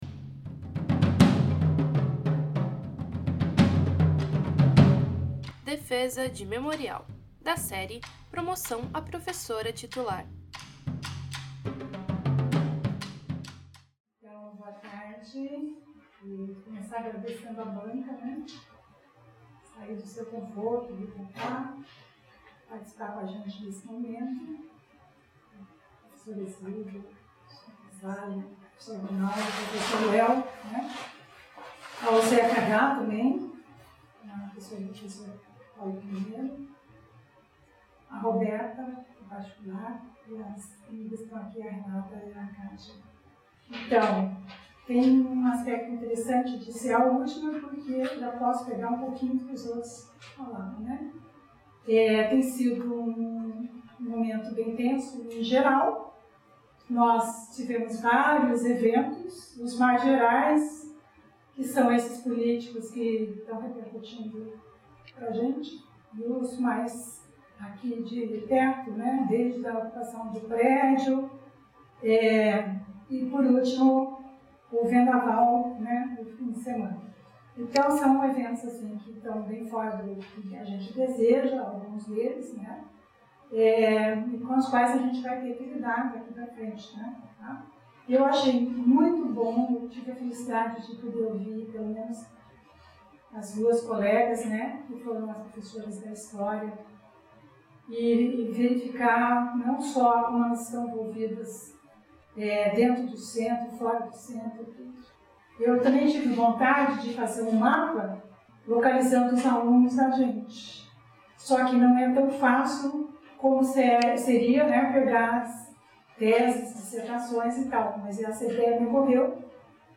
na sala 10/Usos Múltiplos do Departamento de História